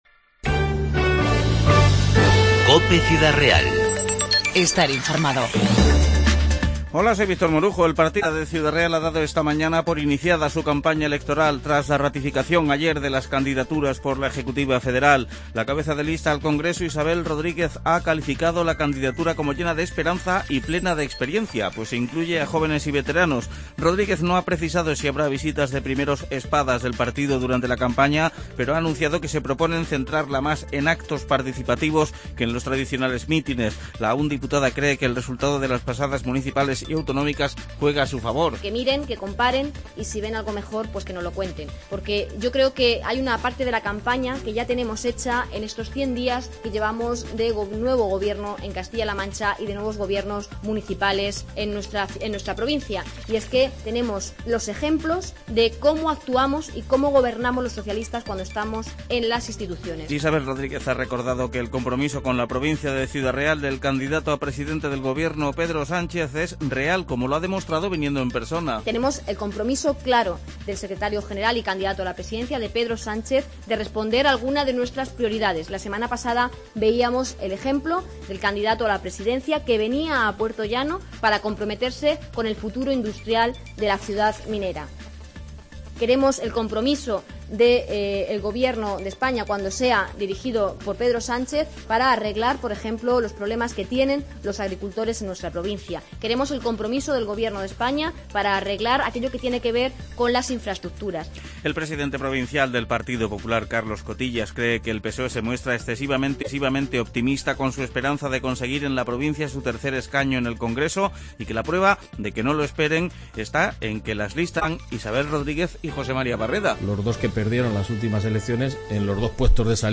INFORMATIVO 19-10-15